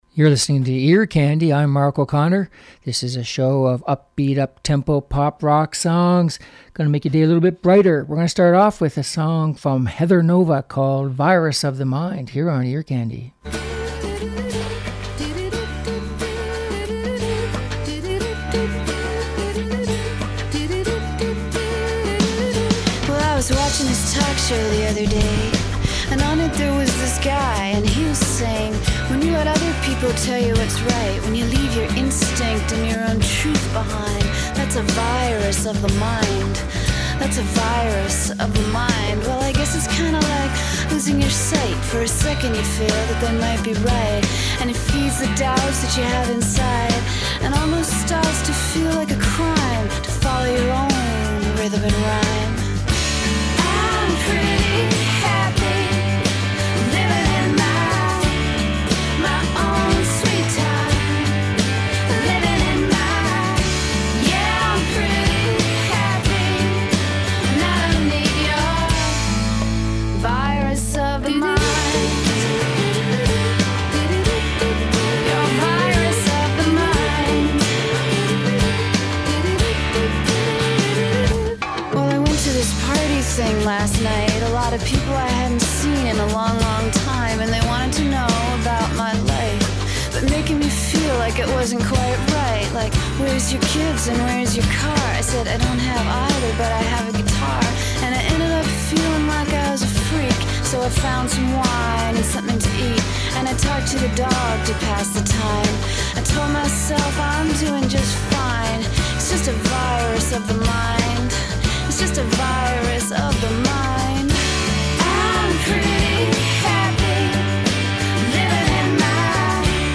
Upbeat Pop and Rock Songs